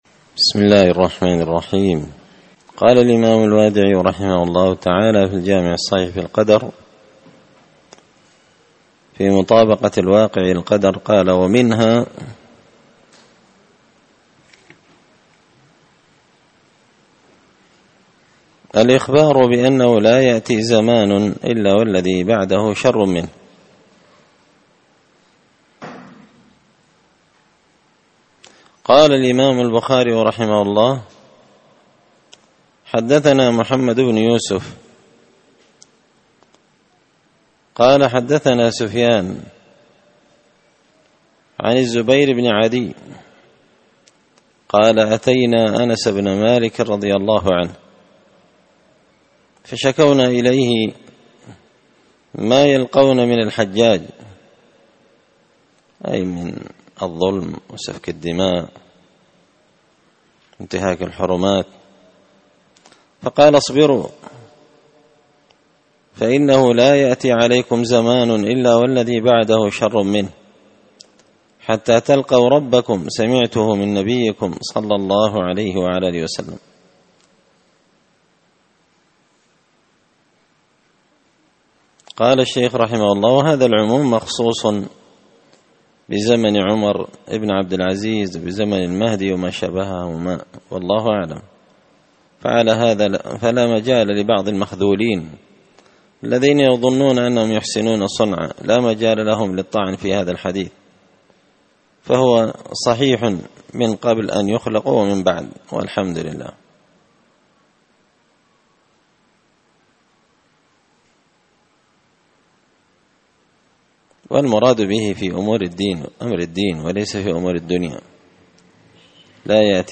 الدرس 105 فصل من مطابقة الواقع على القدر
دار الحديث بمسجد الفرقان ـ قشن ـ المهرة ـ اليمن